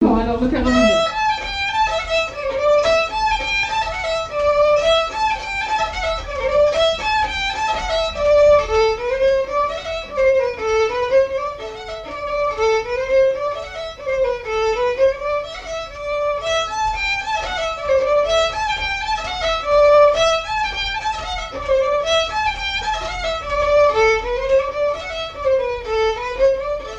branle
Couplets à danser
airs de danses et chansons traditionnelles
Pièce musicale inédite